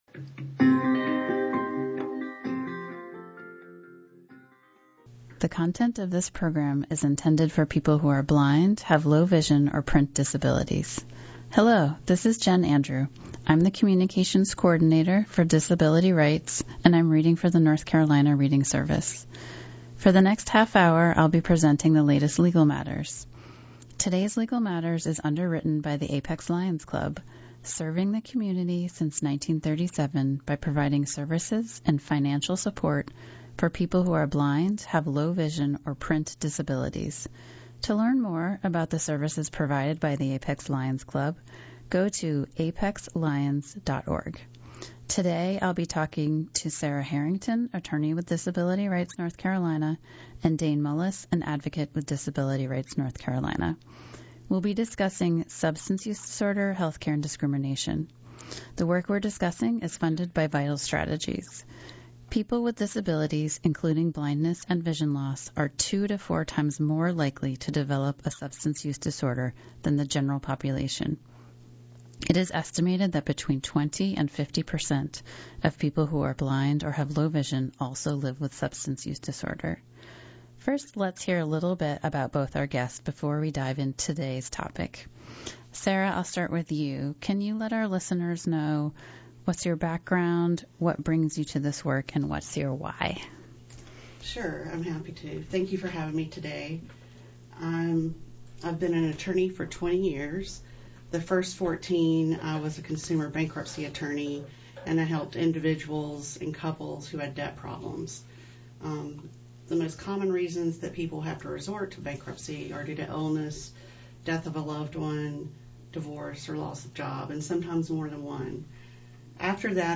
We had a lively conversation about student’s rights in school, and how to help your children with disabilities and others in your family navigate the holiday season.